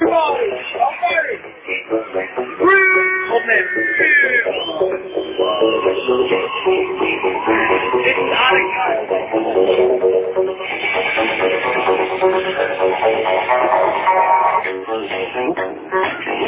I heard it yesterday at Salvationz in the Netherlands.